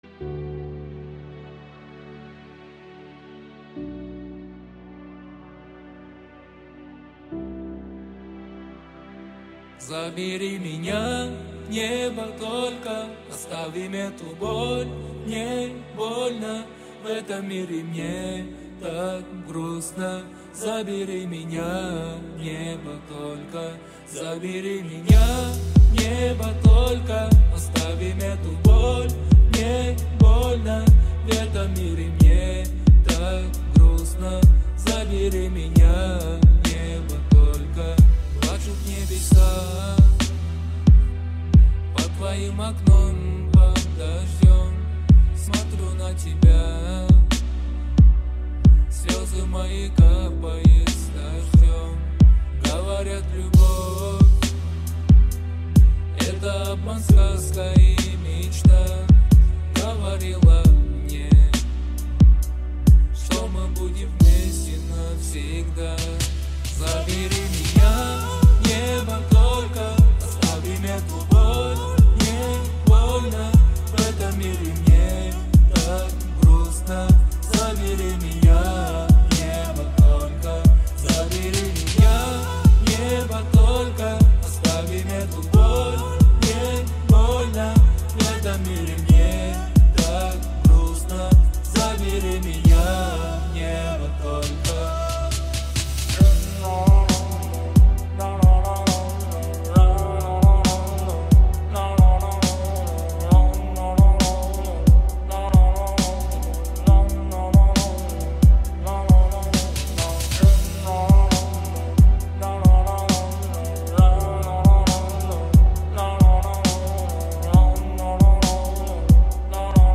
• Категория: Русская музыка